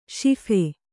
♪ śiphe